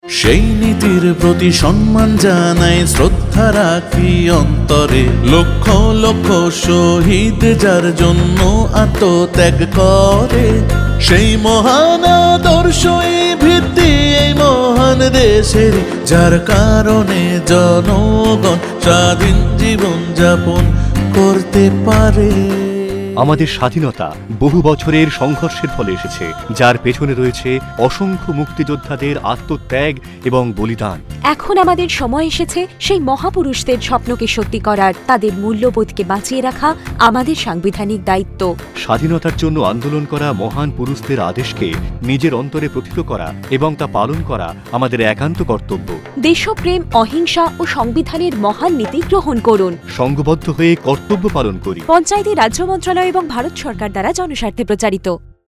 37 Fundamental Duty 2nd Fundamental Duty Follow ideals of the freedom struggle Radio Jingle Bangla